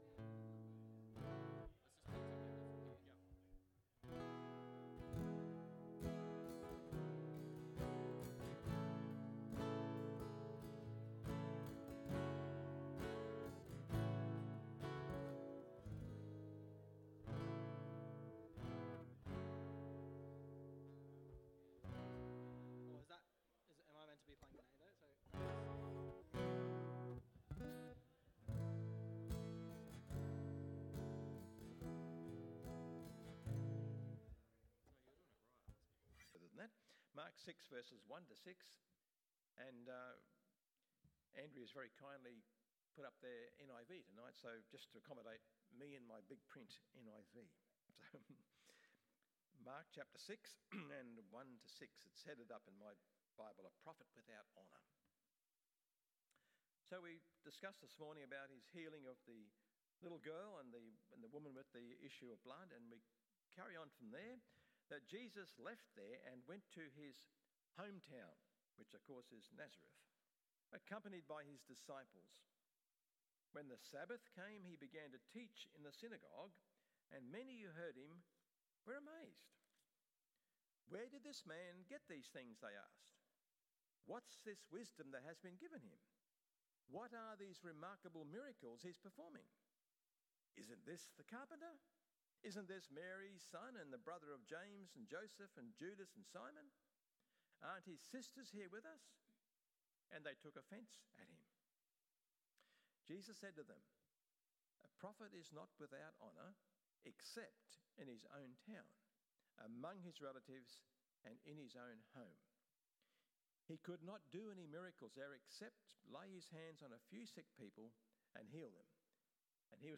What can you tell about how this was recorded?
Welcome Unwelcome Home PM Service